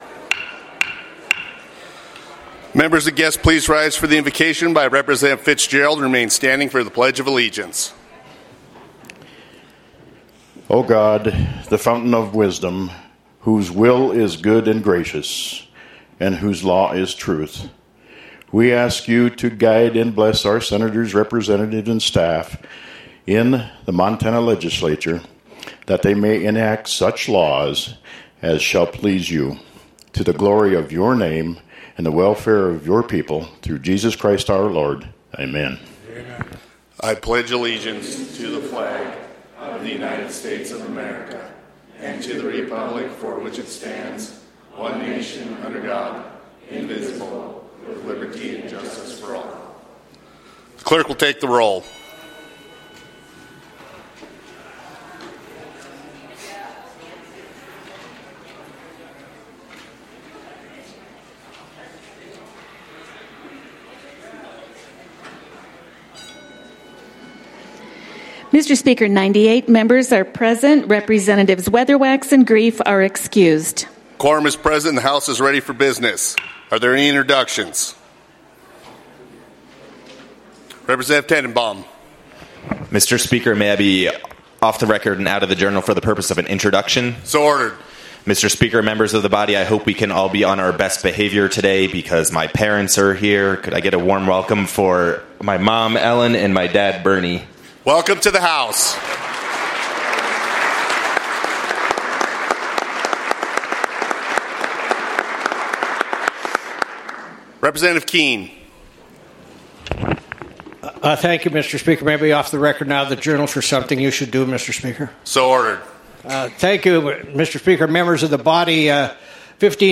House Floor Session